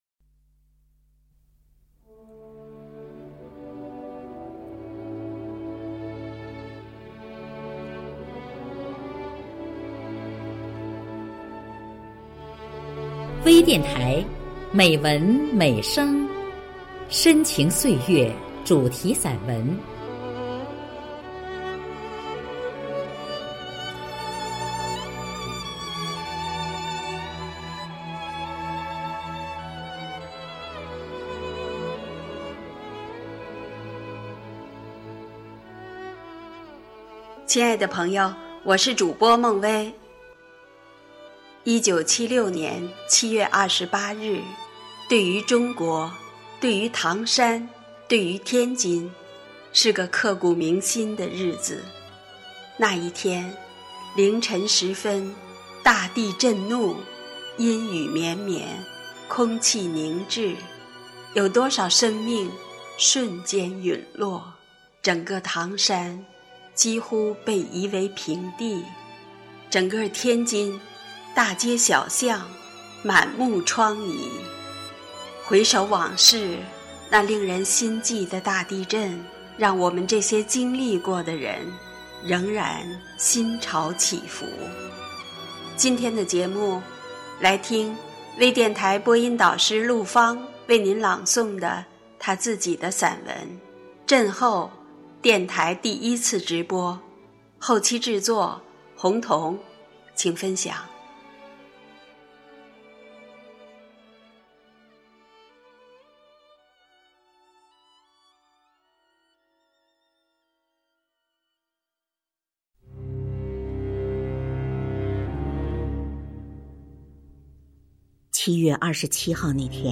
多彩美文 专业诵读 精良制作 精彩呈现